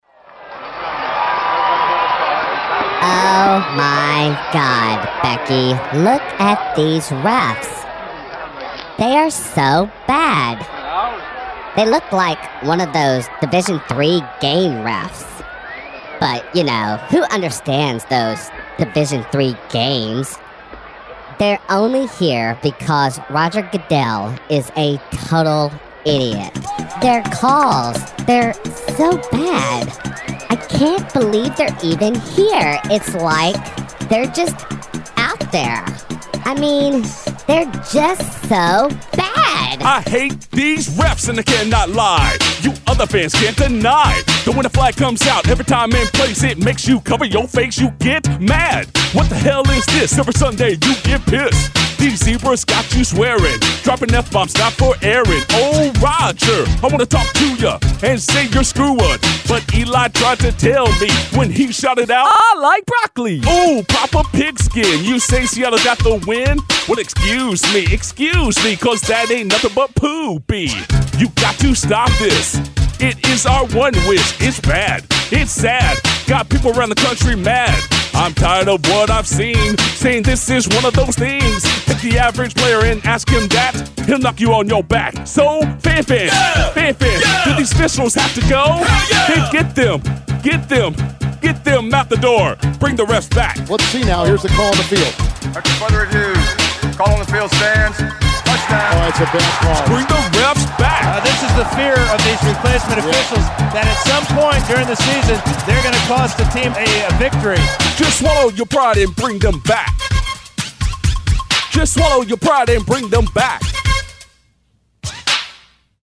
New School Parody: Bring The Refs Back - CBS Texas